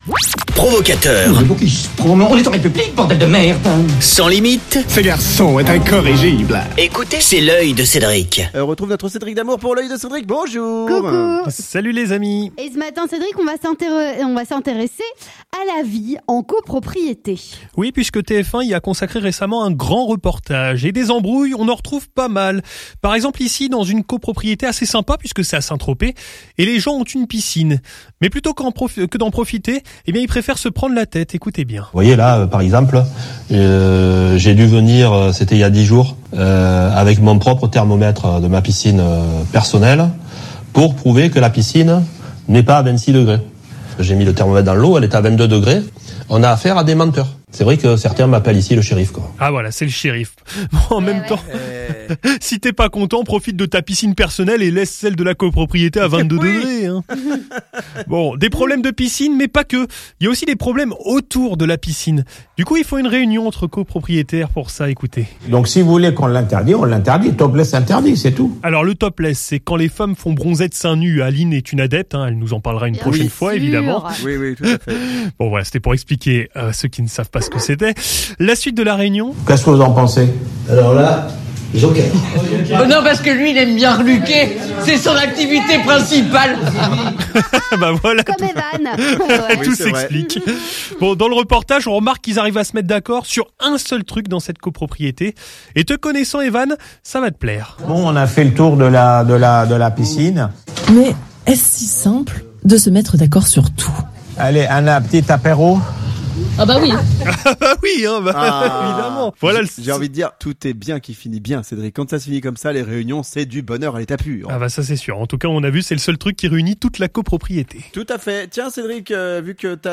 Reportage dans une copropriété à Saint-Tropez....